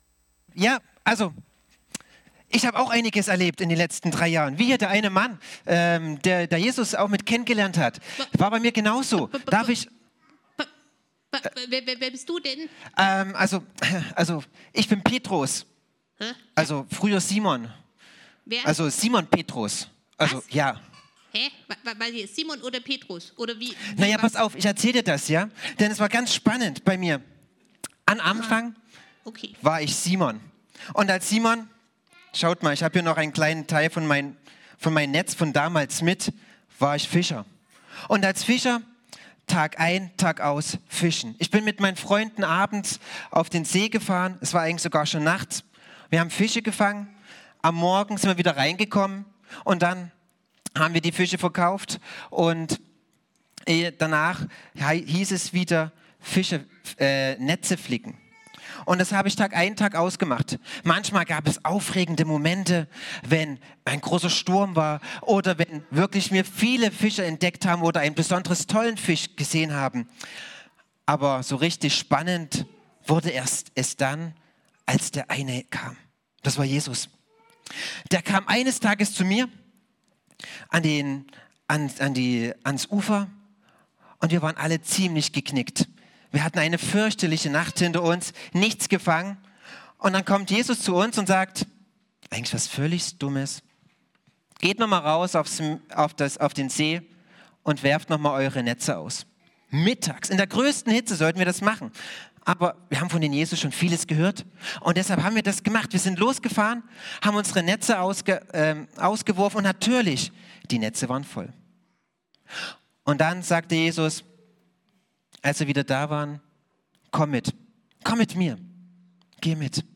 Familiengottesdienst: Jesus geht mit